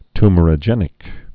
(tmər-ə-jĕnĭk, ty-)